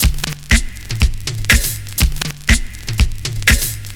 I THINK Drum Break (121.3bpm).wav